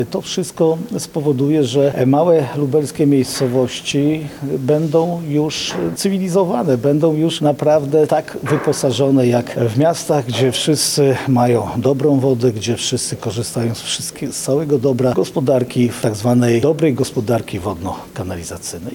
Jarosław Stawiarski – mówi Jarosław Stawiarski, Marszałek Województwa Lubelskiego.